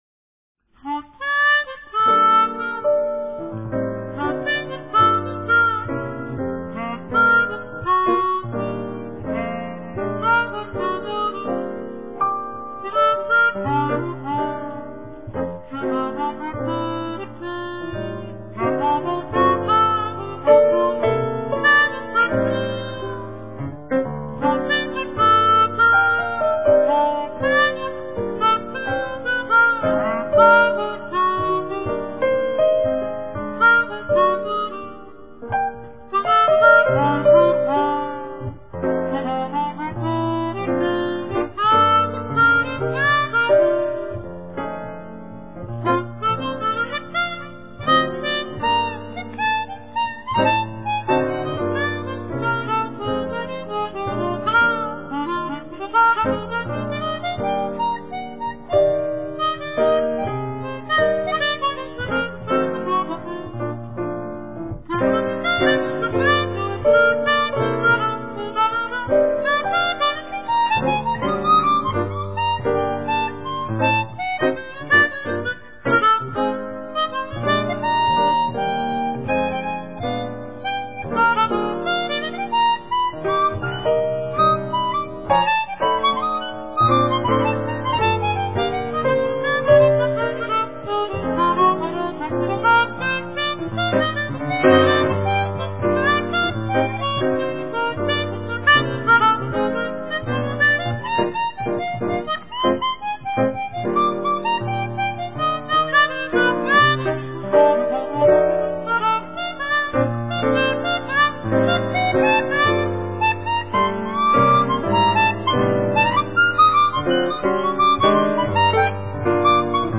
Piano solo - live performance
armonica cromatica